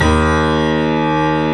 Index of /90_sSampleCDs/E-MU Producer Series Vol. 5 – 3-D Audio Collection/3DSprints/3DYamahaPianoHyb